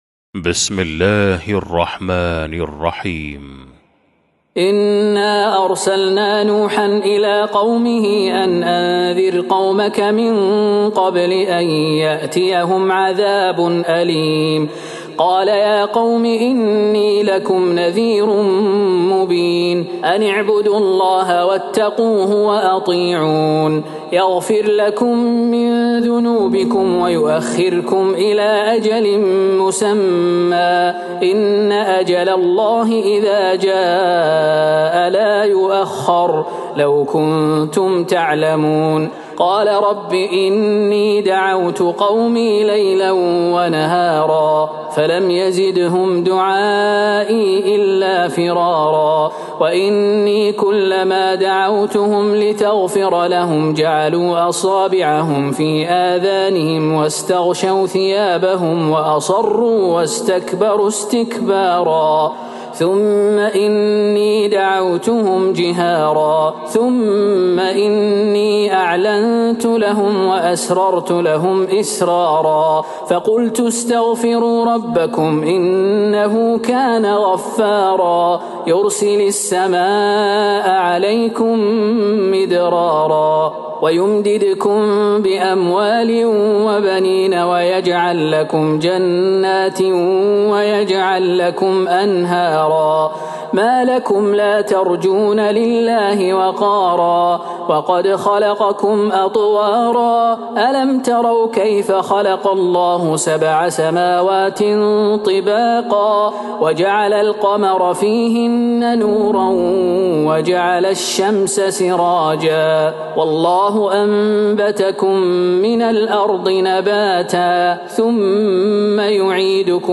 سورة نوح Surat Nooh > مصحف تراويح الحرم النبوي عام 1443هـ > المصحف - تلاوات الحرمين